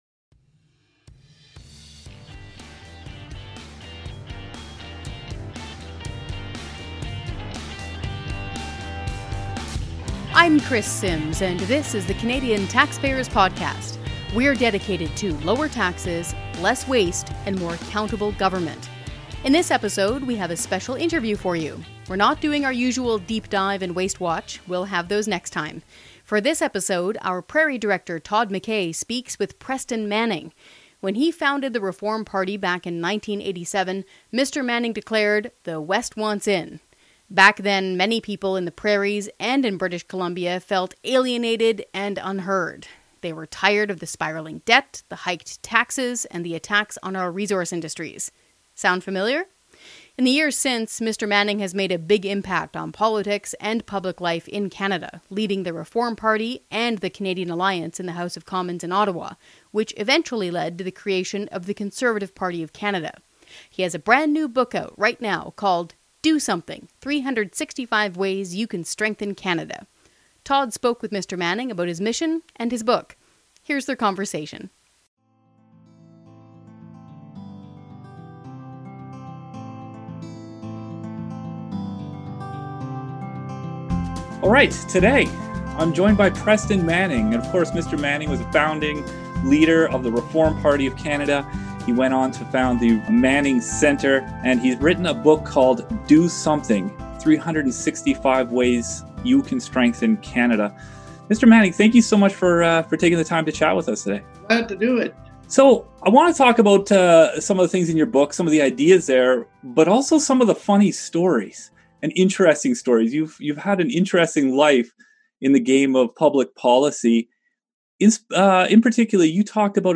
#17 INTERVIEW Preston Manning
The Canadian Taxpayers Podcast sat down with Preston Manning, one of the most well-known and influential politicians in Canada today.